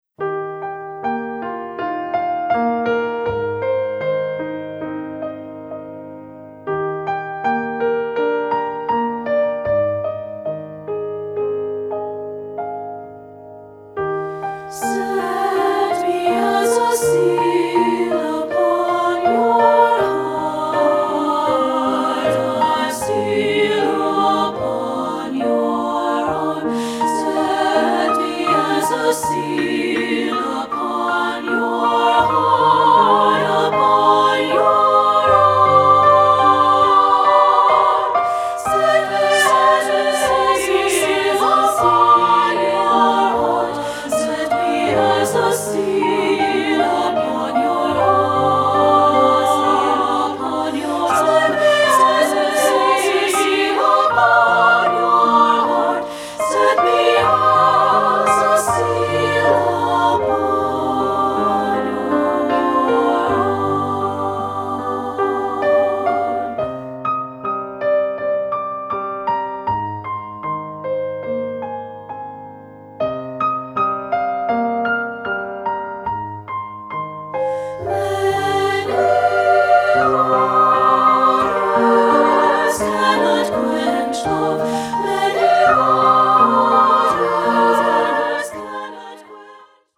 Choral Women's Chorus
Voicing
SSA